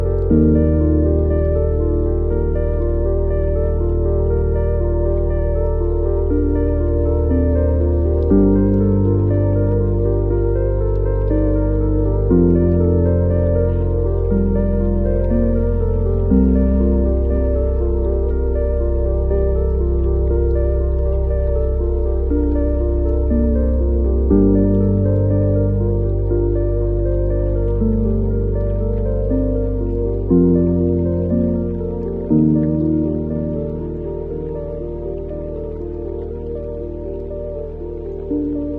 Two AI Architectural Orbs Collide Sound Effects Free Download
Two AI architectural orbs collide and perfectly merge into one.